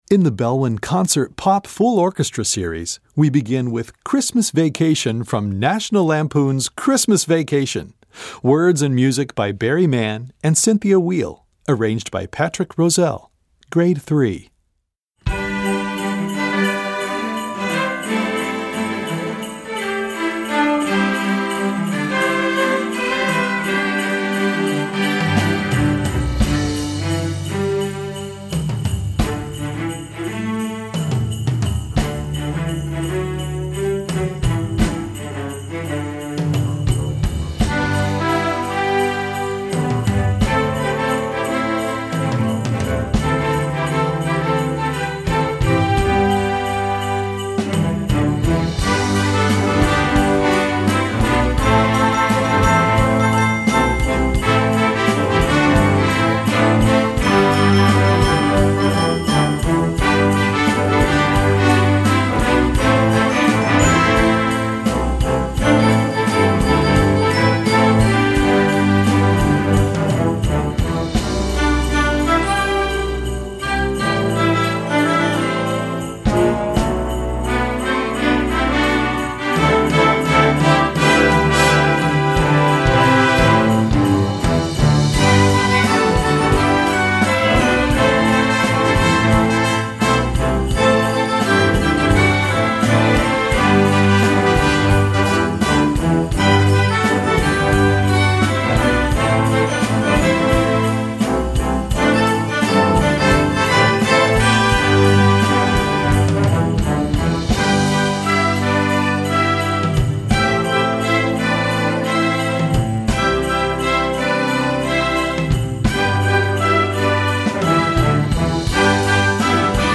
Gattung: Sinfonieorchester
Besetzung: Sinfonieorchester